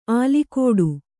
♪ ālikōḍu